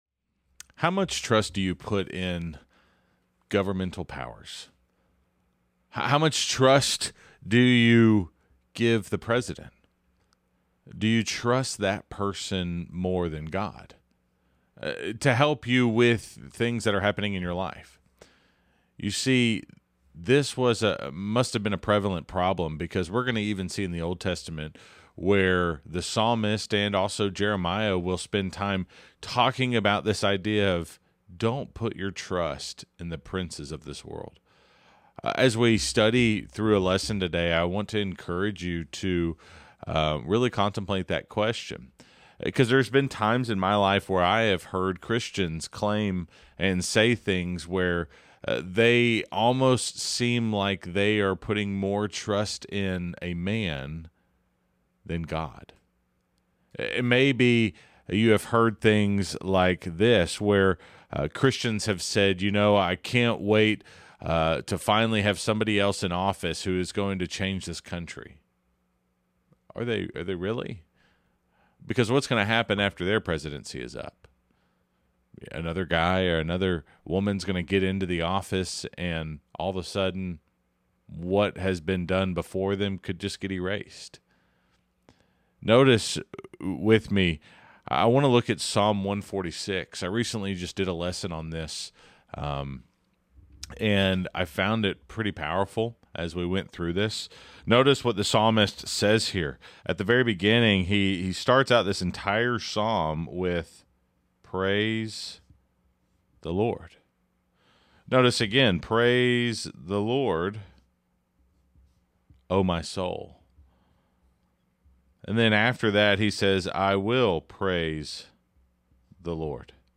Tune into this lesson from our Preacher